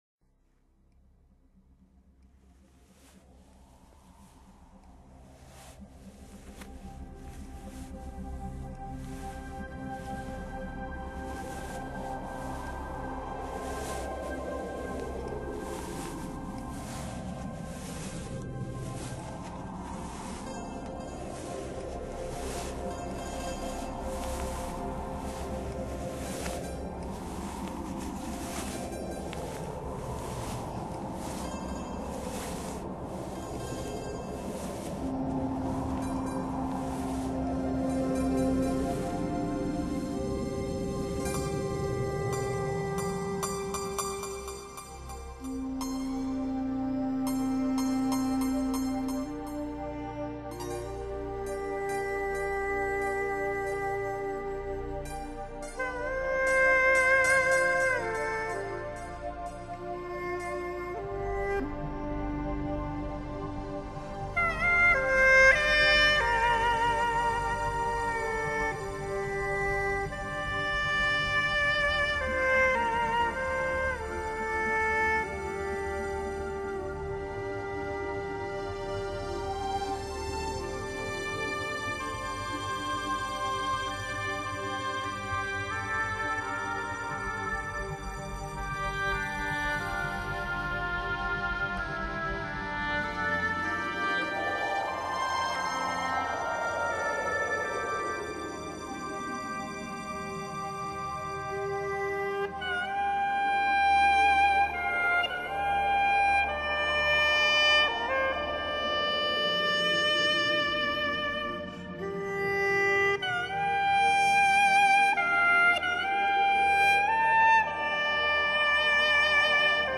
笙
双簧管